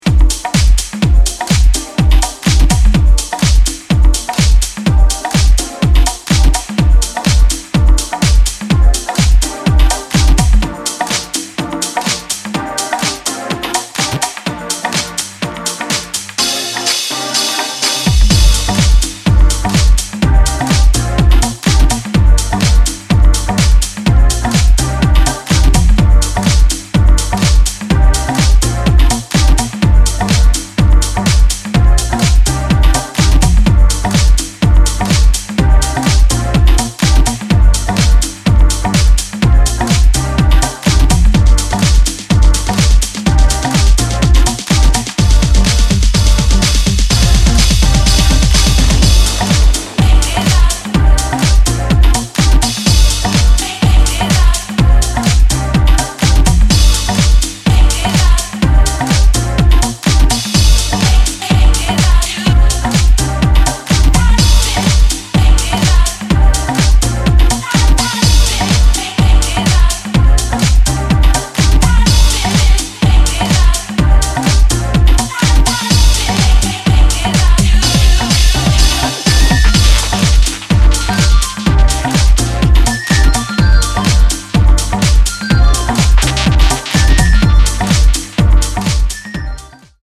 Tripped-out, leisurely house and breaks